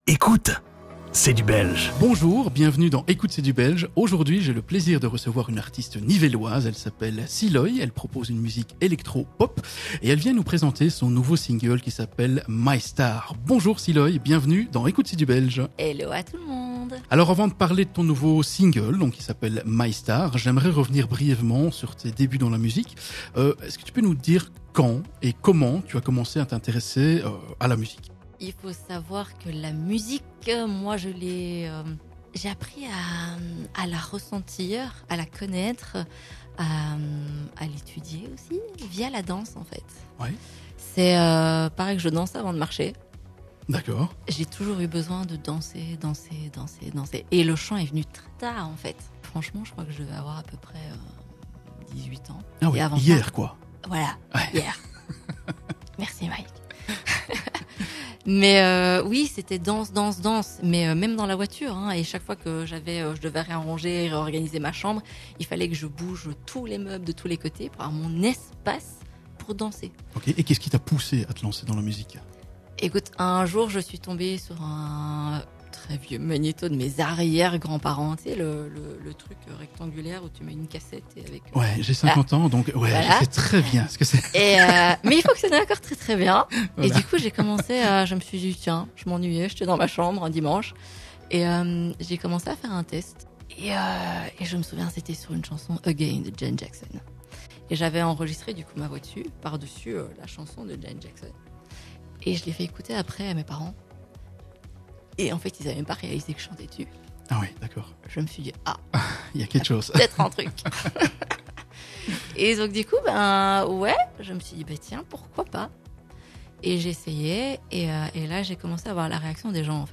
L'interview C'est dans les studios d'Ultrason